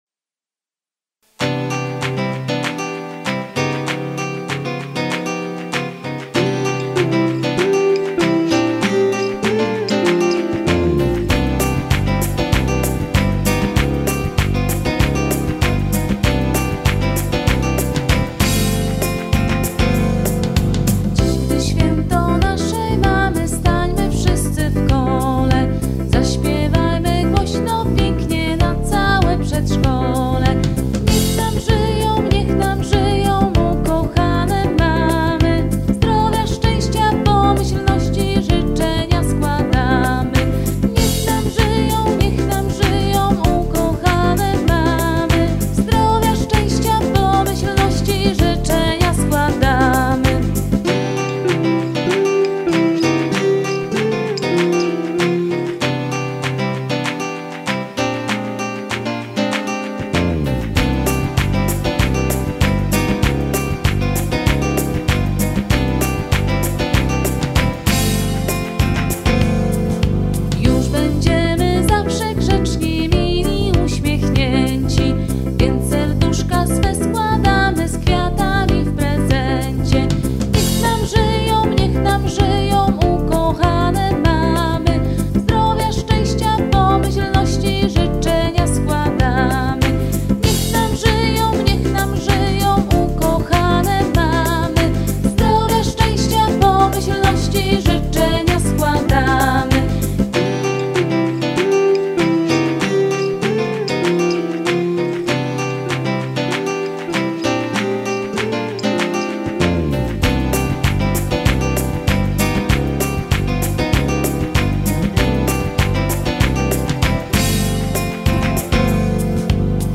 Piosenka